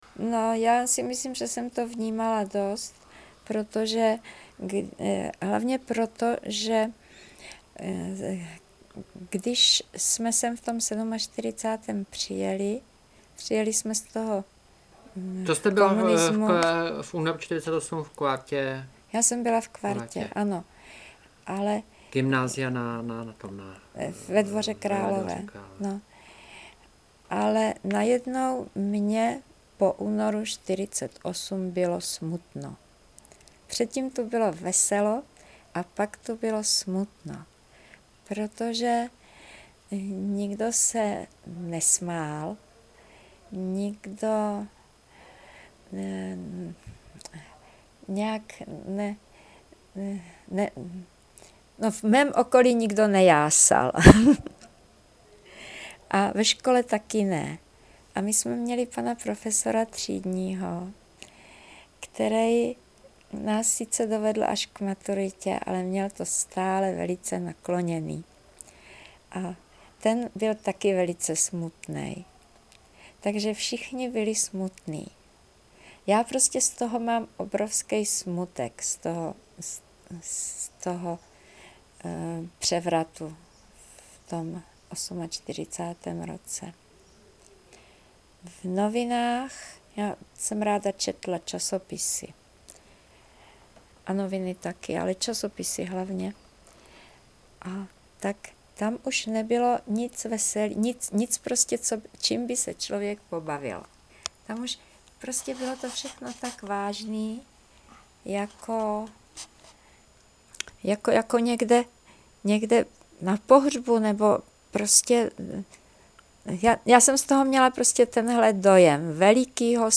Klip z vyprávění